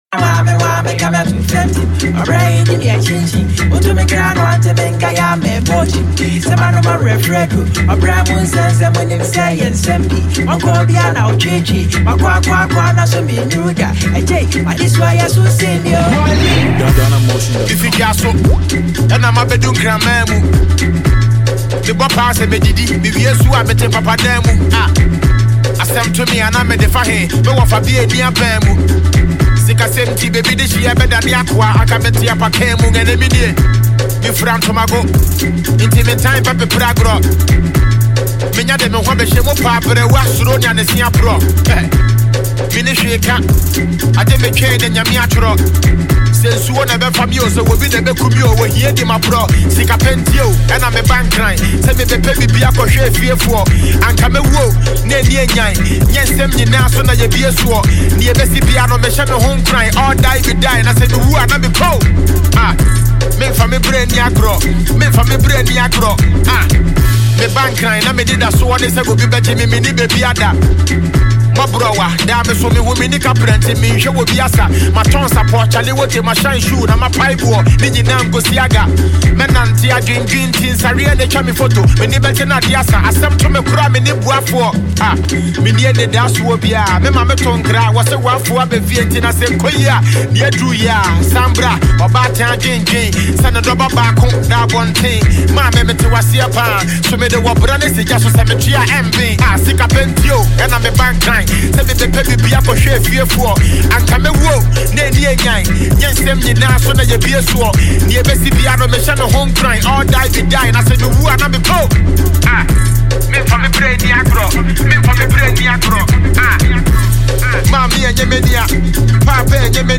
hot pop song